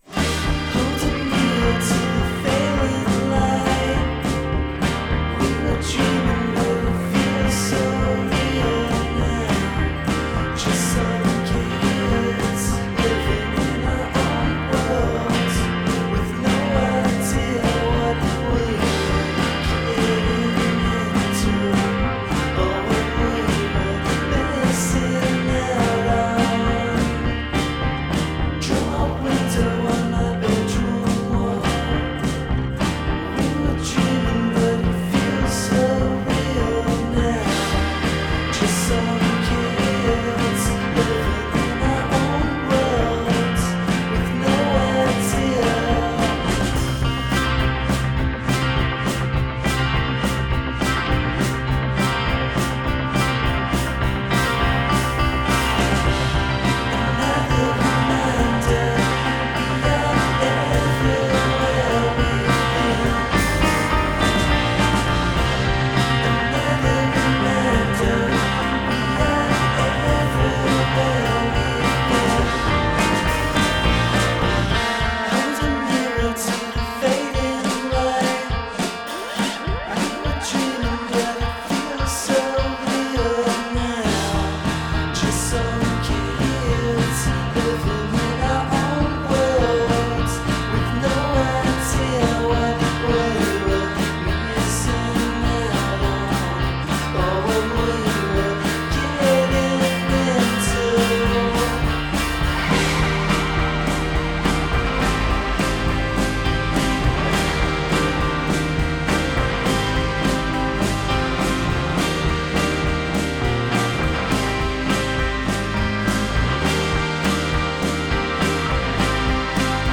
Unmixed TRACK
We put a mixed, but unmastered, song of mine through the free-trial version of the software.
The song begins as an acoustic song and ends with full instrumentation, including drums, so we could hear how it treats both.